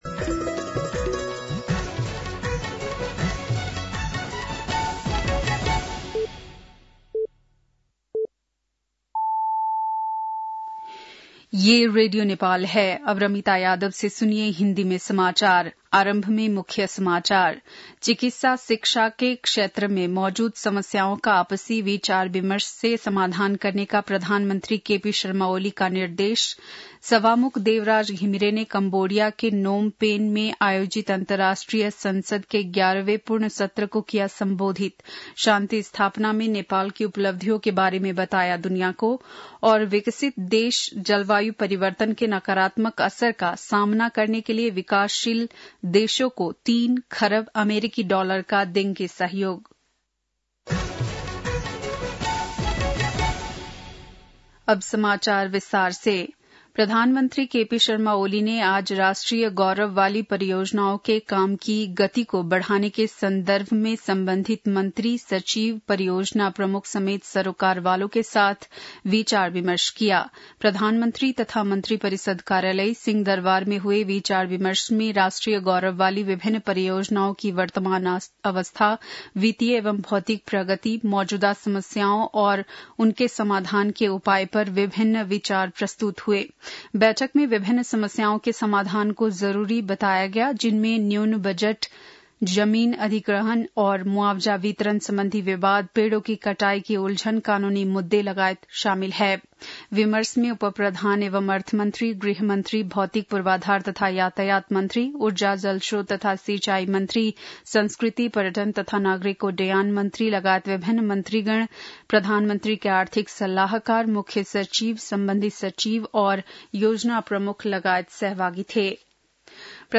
बेलुकी १० बजेको हिन्दी समाचार : १० मंसिर , २०८१
10-pm-hindi-news-8-09.mp3